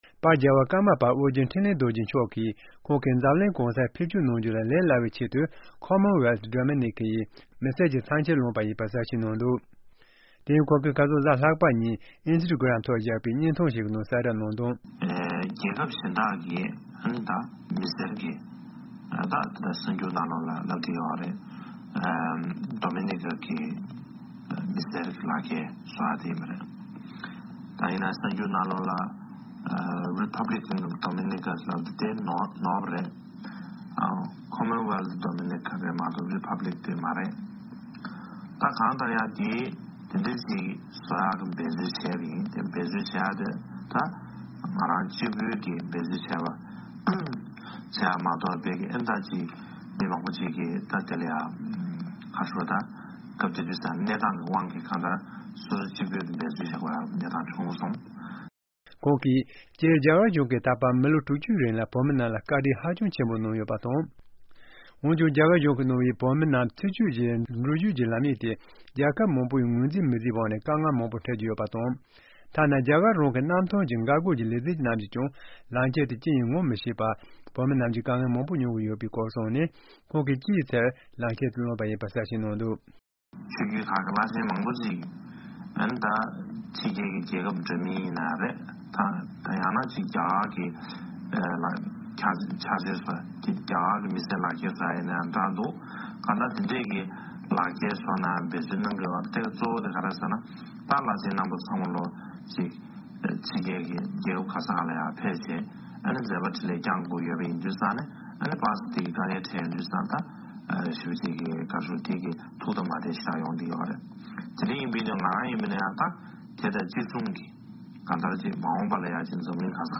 ཕྱོགས་བསྒྲིགས་དང་སྙན་སྒྲོན་ཞུ་ཡི་རེད།།